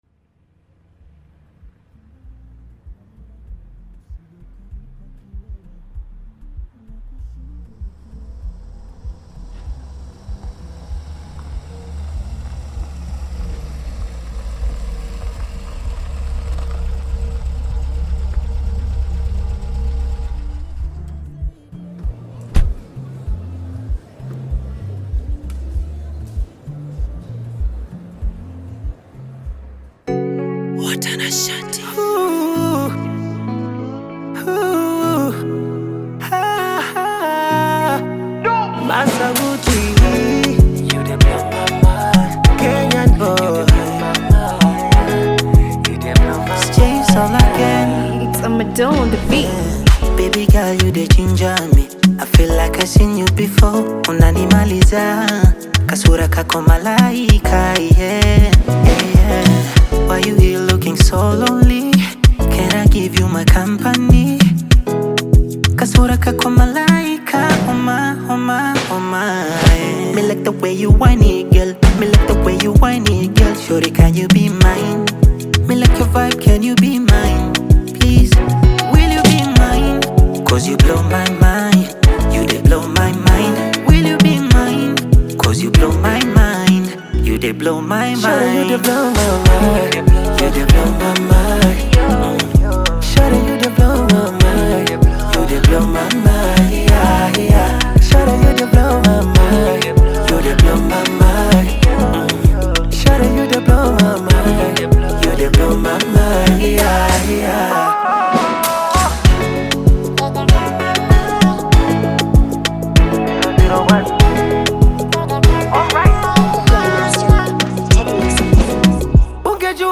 smooth vocal delivery and contemporary sound